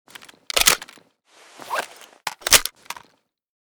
vityaz_reload.ogg.bak